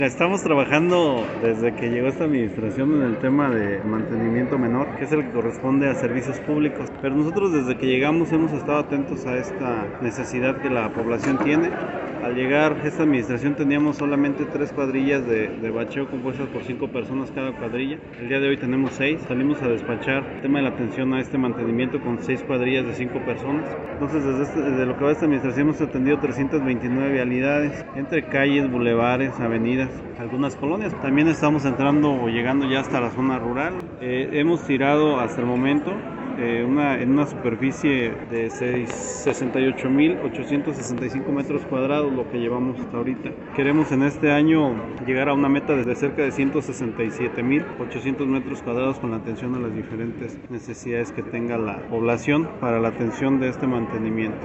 AudioBoletines
Rogelio Pérez Espinoza – Titular de Servicios Públicos